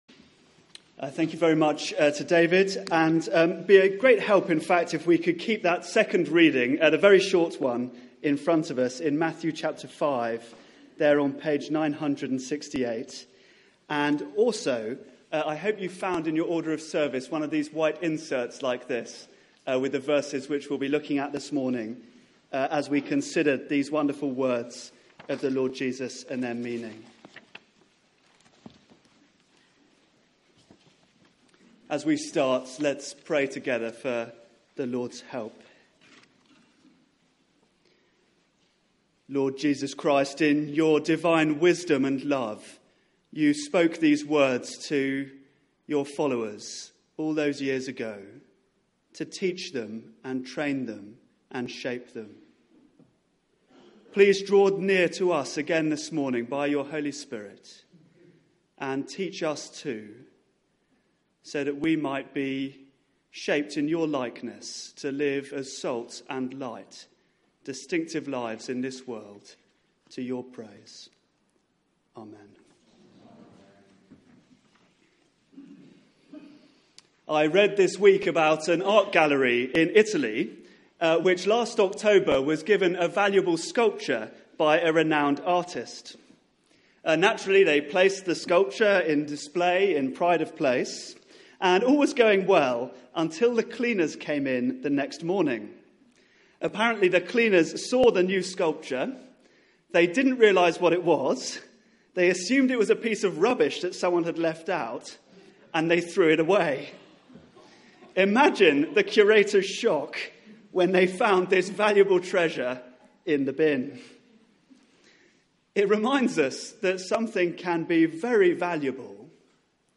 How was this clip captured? Media for 11am Service on Sun 23rd Oct 2016 11:00 Speaker